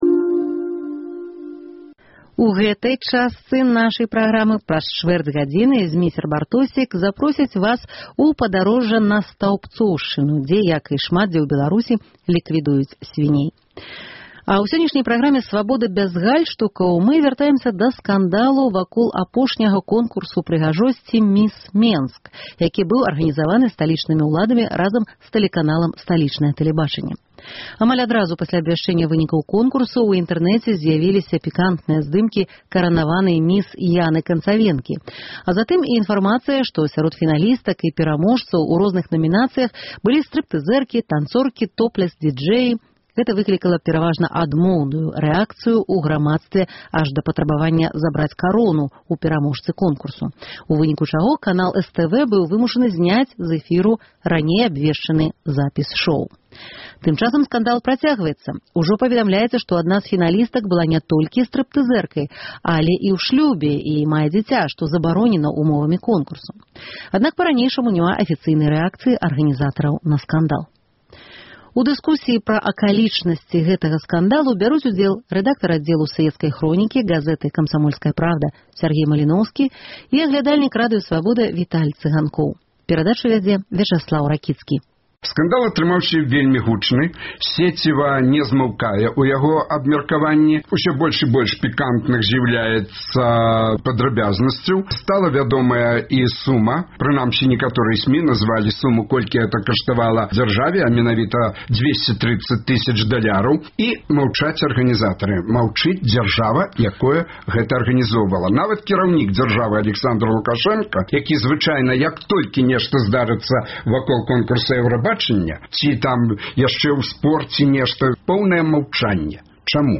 Абмеркаваньне гарачых тэмаў у студыі Свабоды.